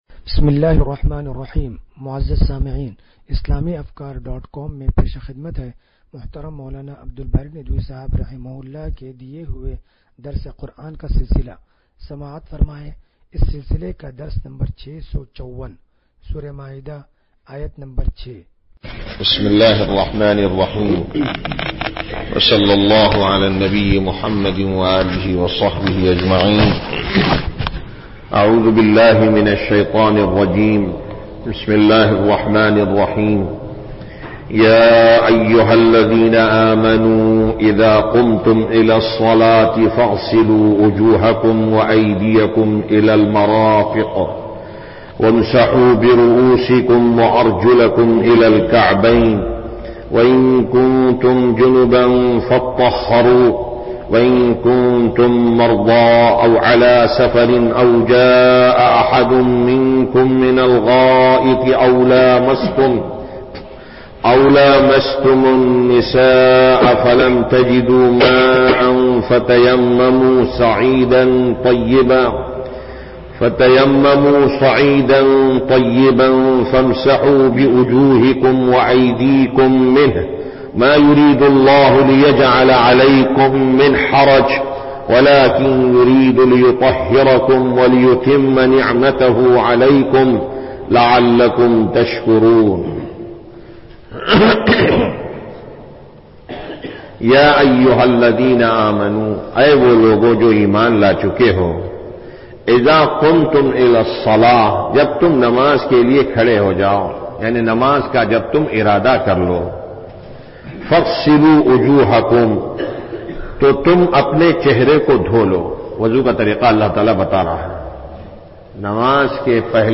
درس قرآن نمبر 0654
درس-قرآن-نمبر-0654.mp3